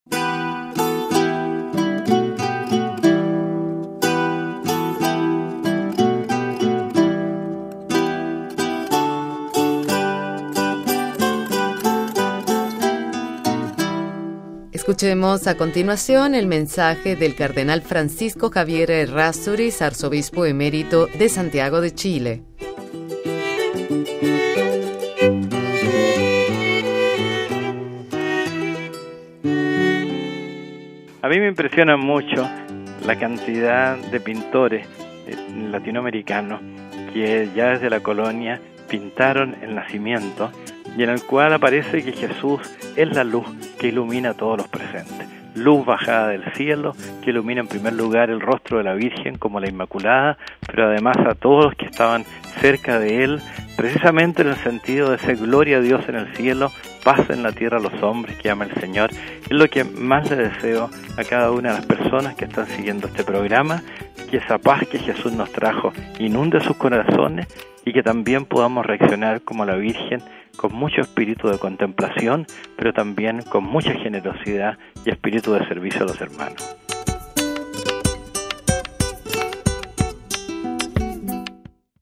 Cuando estamos celebrando este período navideño los obispos, los pastores de América Latina desde sus países se dirigen a todos nuestros oyentes con un mensaje de Navidad.